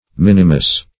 Minimus \Min"i*mus\, n.; pl.